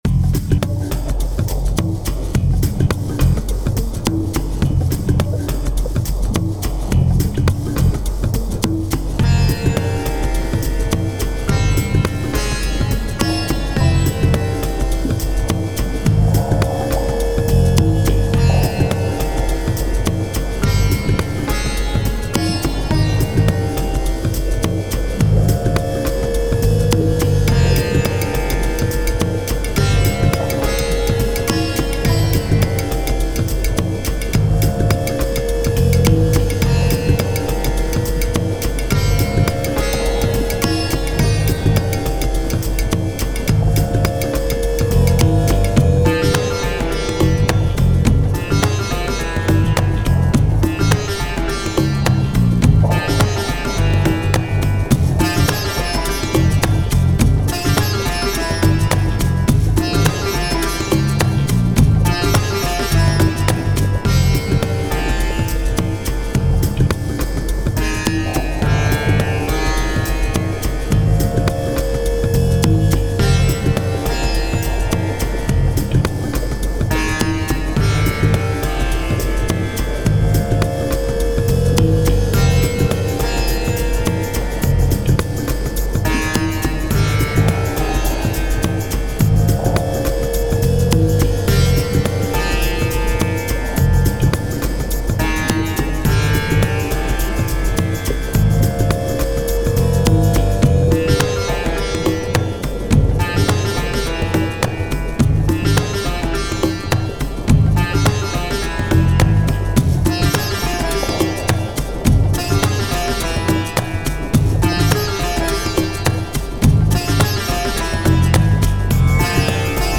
タグ: ドキドキ/緊張感 フィールド楽曲 冒険 コメント: 溶岩地帯をイメージした楽曲。